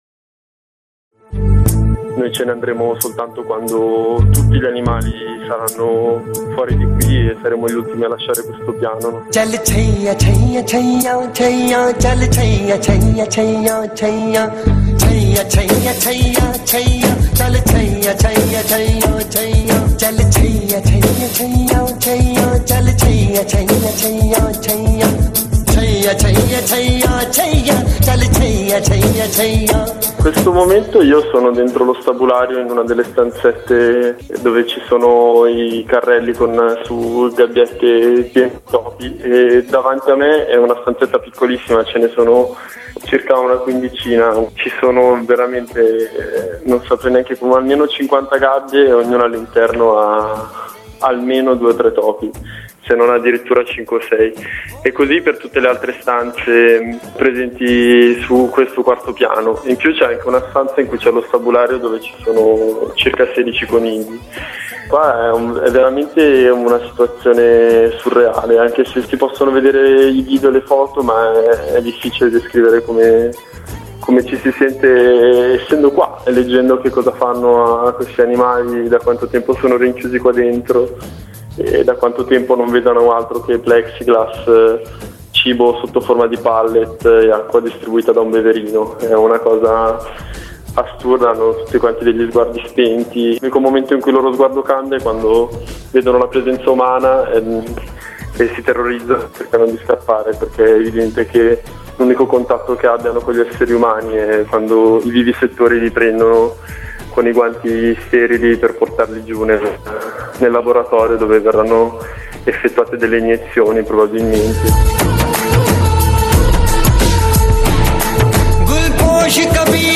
Dopo dieci ore di assedio e trattative, vengono liberati centinaia di animali. Qui una radiocronaca della giornata, con voci dai vari fronti dell’azione (fonte: RadioCane).